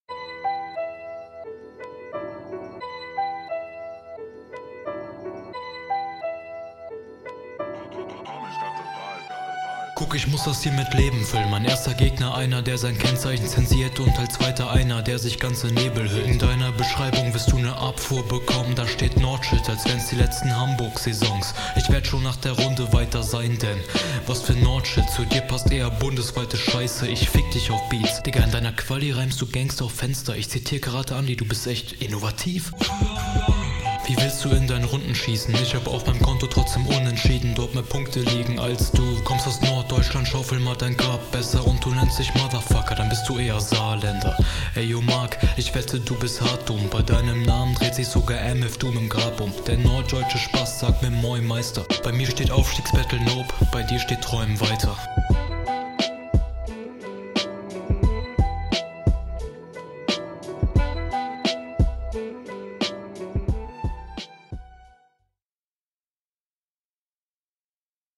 flow ok stimmeinsatz fehlt irgendwie komplett aber dafür halbwegs im takt die karate andi stelle …